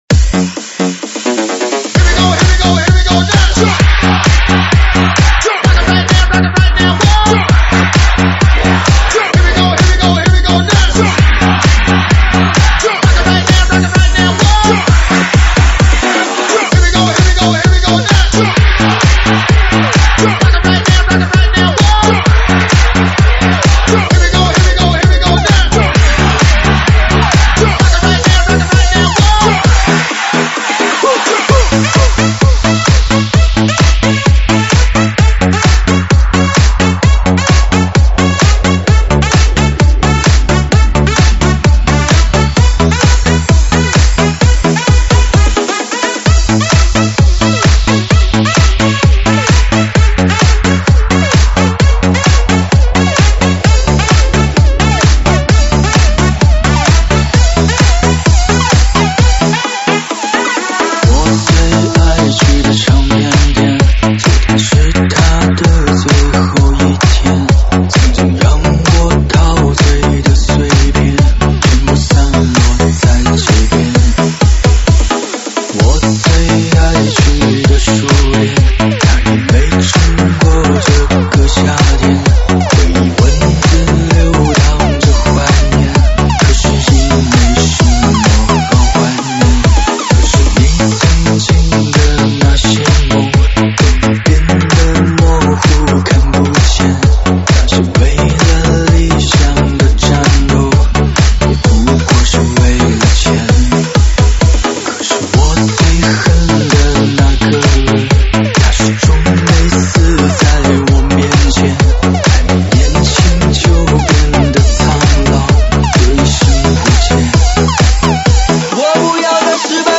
电子Electro